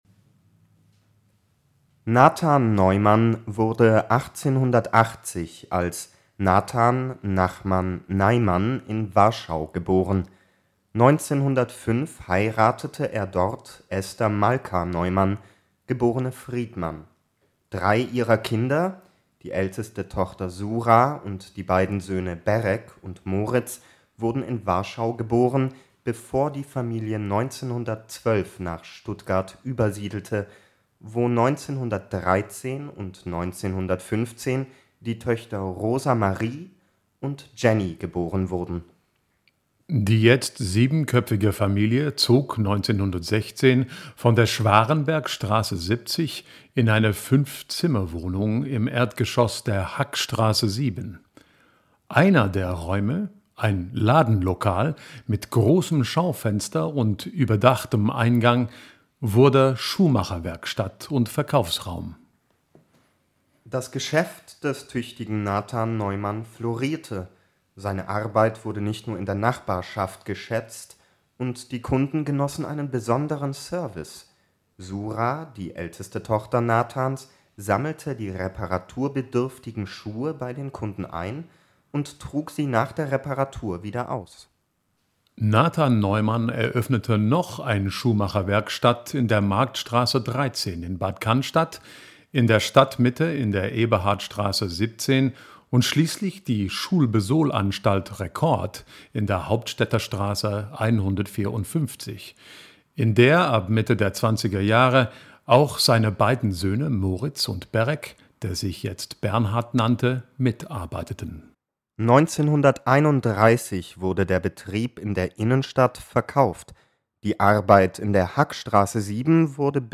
Sprecherensembles der Akademie für gesprochenes Wort